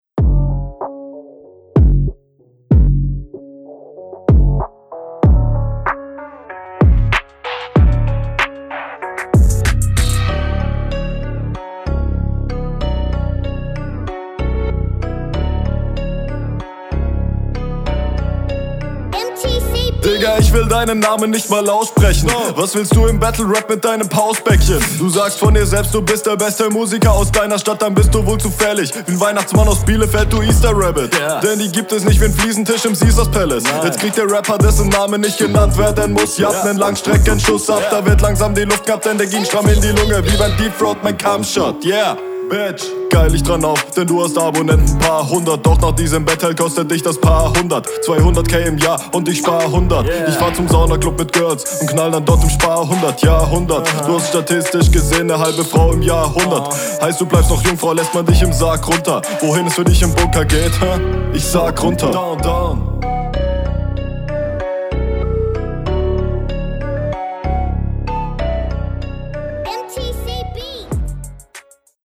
Battle Rap Bunker
Hinrunde 1